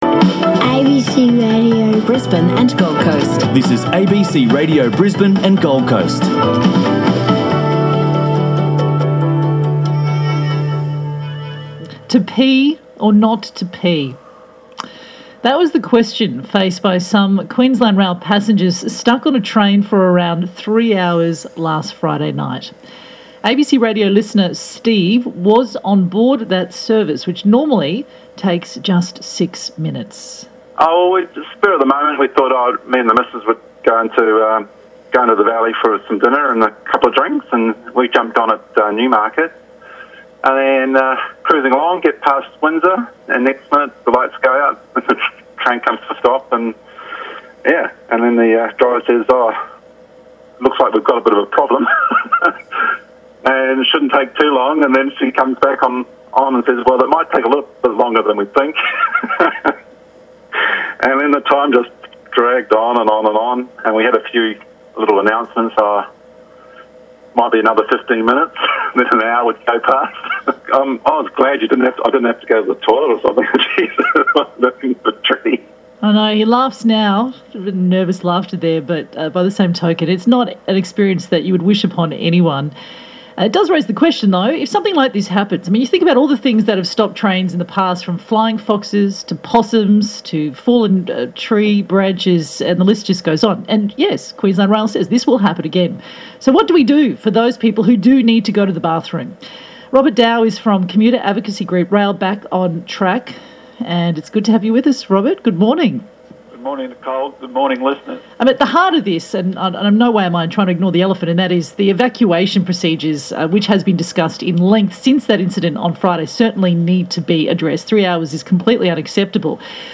Here is the interview -->